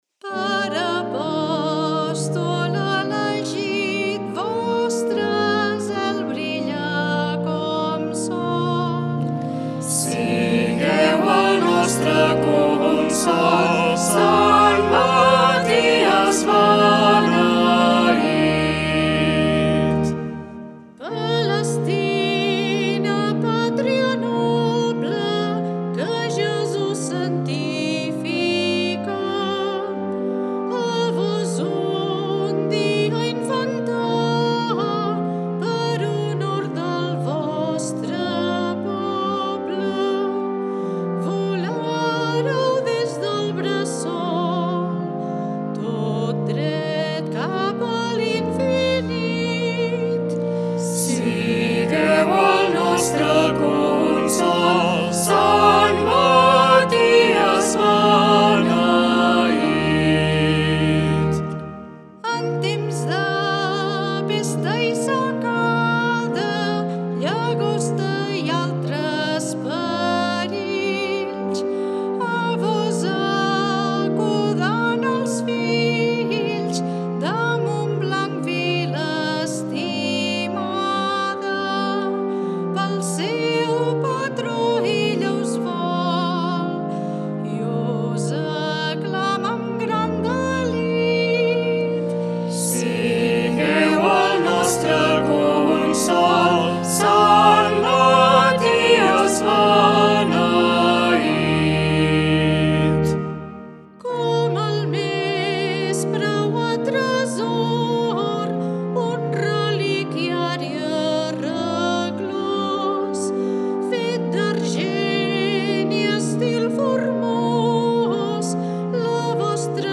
A continuació trobareu diverses músiques populars religioses de Montblanc digitalitzades amb motiu de l’Any de la Mare de Déu (setembre 2021- setembre 2022):